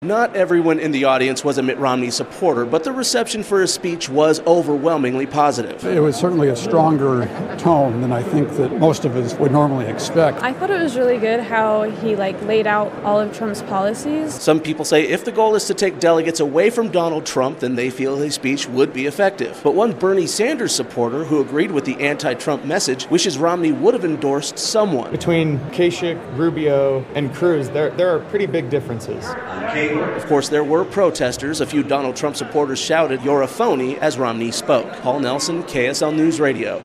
Large crowd comes to hear Romney's speech against Trump
There were not enough seats inside the theater at the University of Utah for everyone who came to Romney's address. The crowd's reaction was overwhelmingly supportive, but there were some critics.